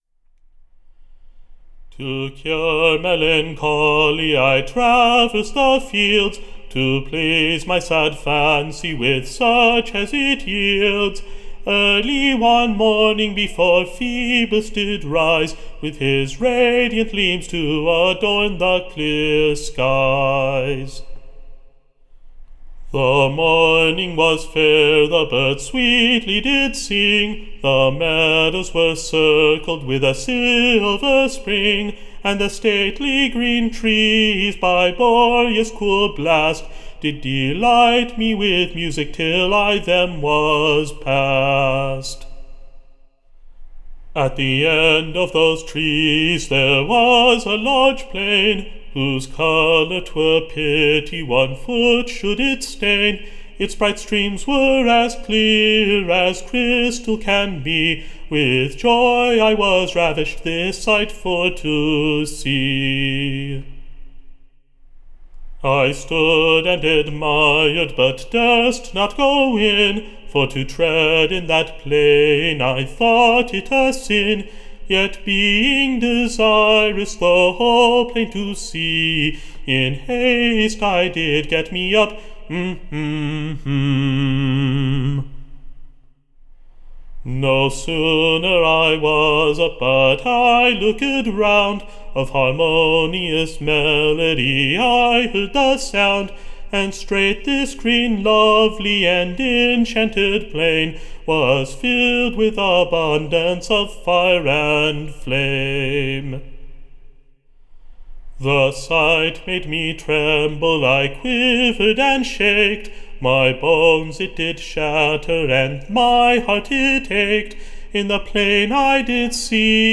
Recording Information Ballad Title CUPID'S POVVER.